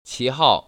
[qíhào] 치하오